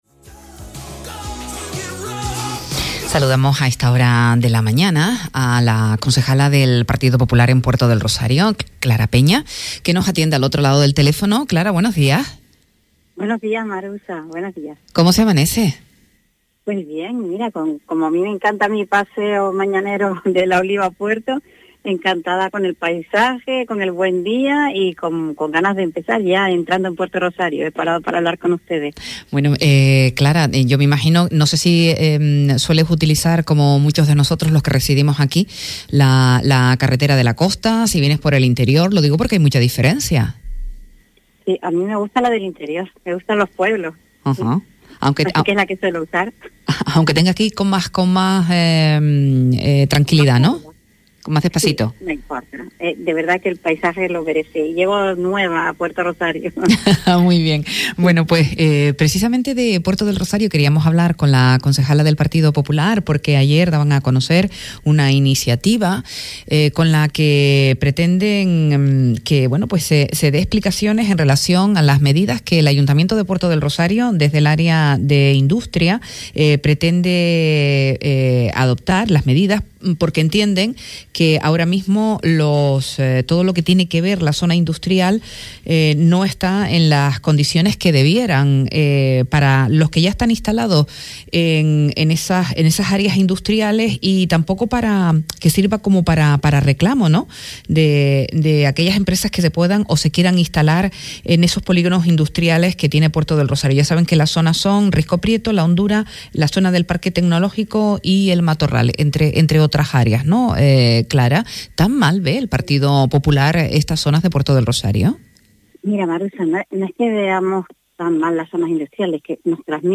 “A Primera Hora” entrevista a Clara Peña, concejala del Partido Popular en Puerto del Rosario quien ha planteado mejoras en los polígonos industriales de la capital.